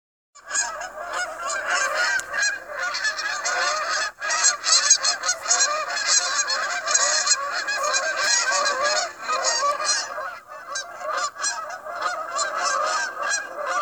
Look and listen overhead and you may also observe large flocks of Canada Geese or even Snow Geese, honking in their well-known V-formations, headed north to their Arctic breeding grounds.
click here to hear the honks of migrating Canada Geese
geese.m4a